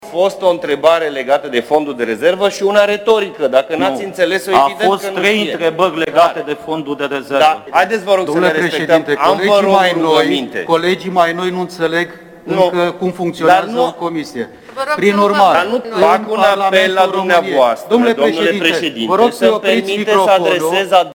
23dec-15-parlamentari-vorbesc-toti-odata.mp3